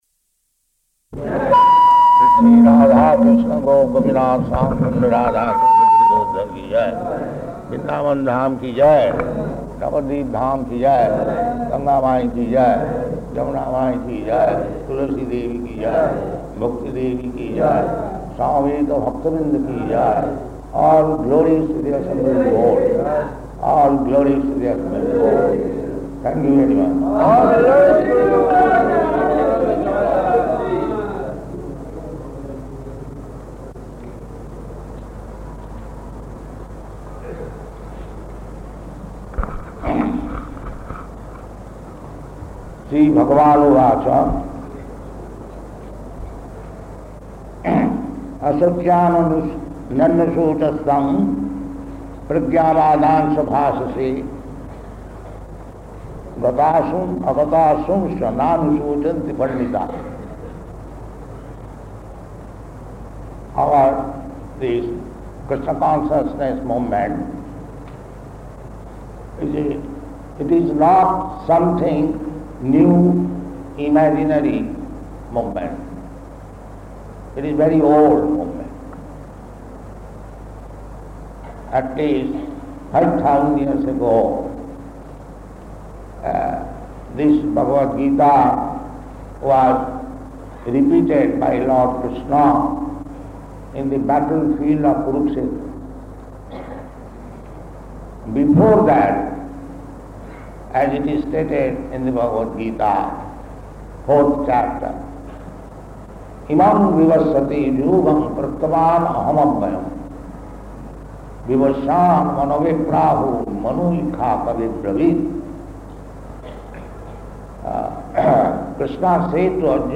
Bhagavad-gītā 2.11 -- Rotary Club Address at Hotel Imperial